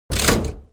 Lever.wav